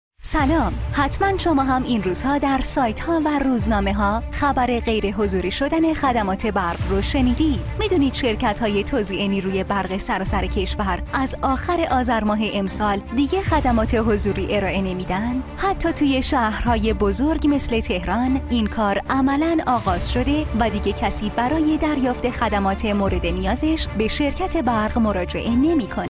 نمونه از تبلیغات پیامک صوتی